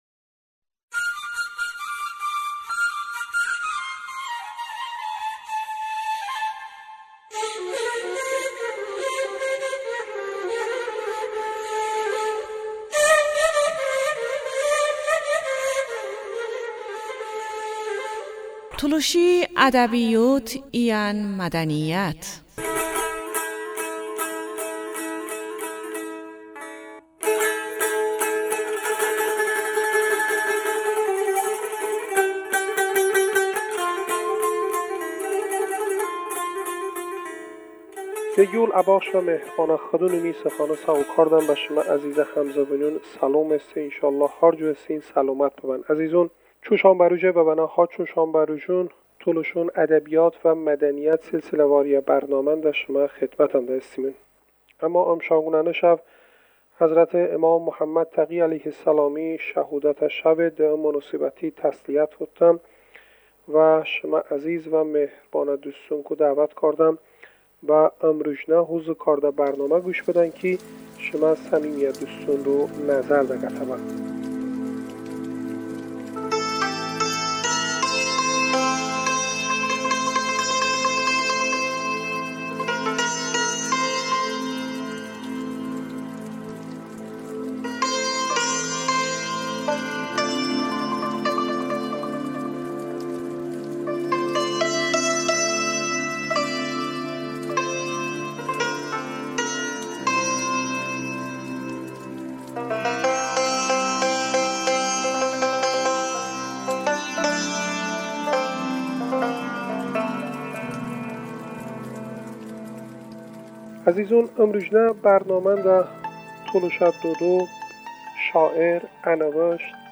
de səntor sazi jə misiği istifadə bə.